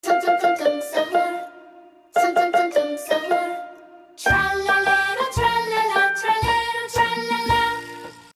Piano Tutorial